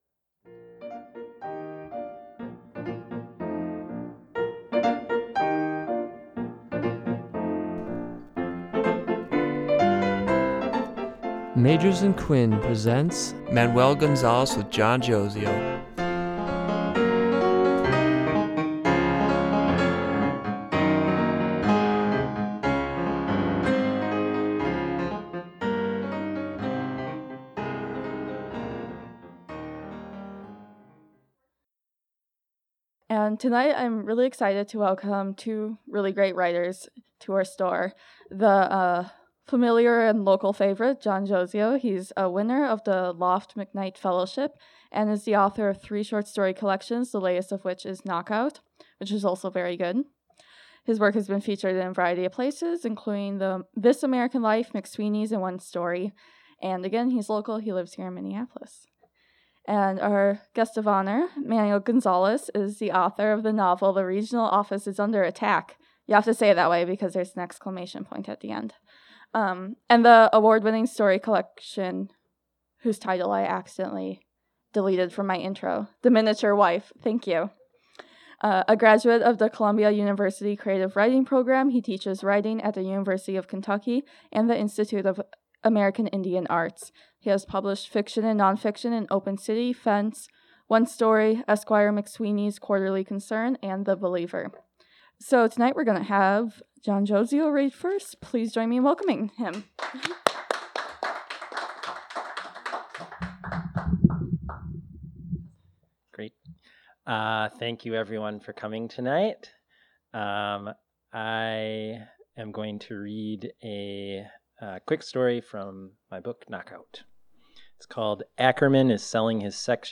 Recorded at Magers and Quinn Booksellers on June 17, 2016.